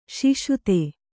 ♪ śiśute